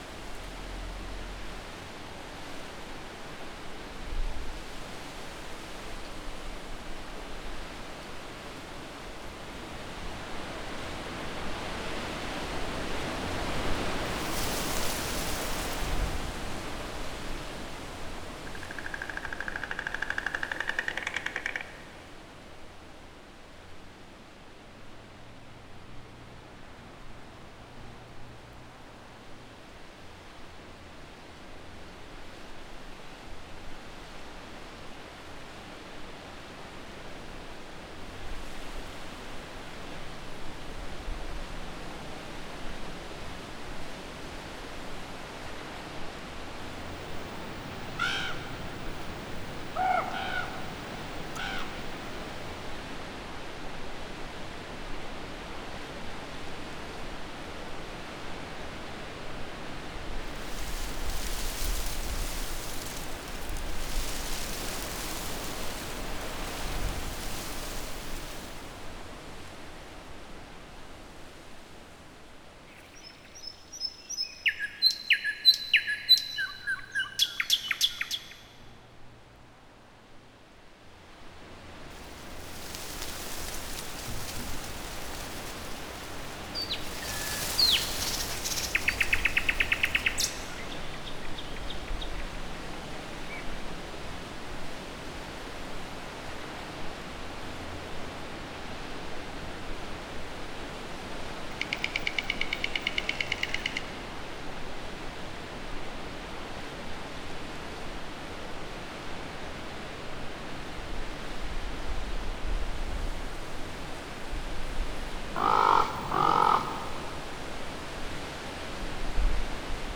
Farm_Atmos_03.wav